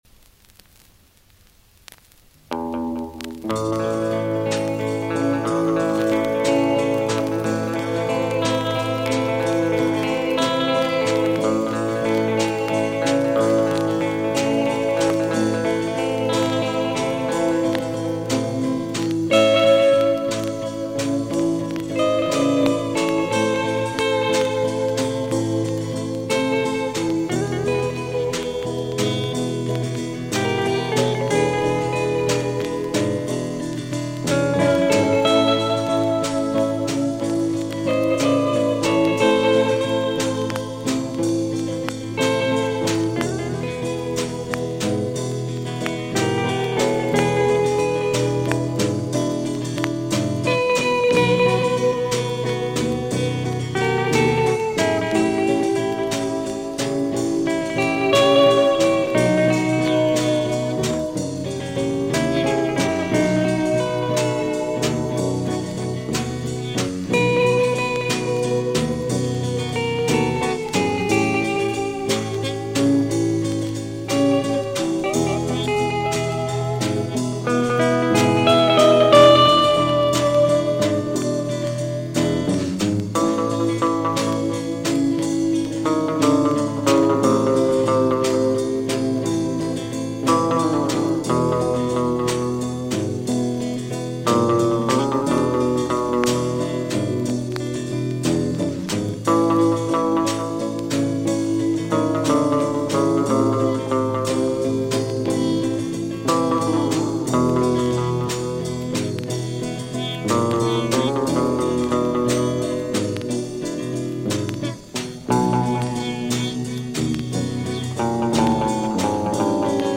Soyez indulgents, c'est un disque qui a beaucoup vécu !!!!